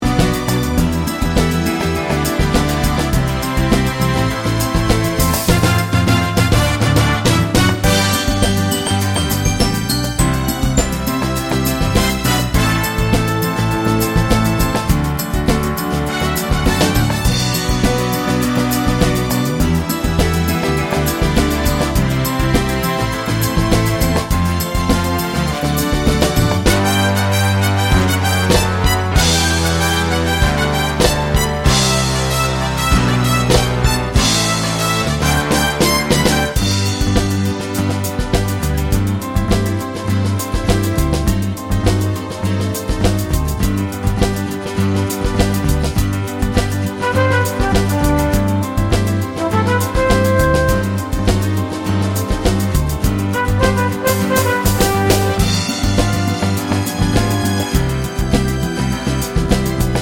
no Backing Vocals Crooners 2:45 Buy £1.50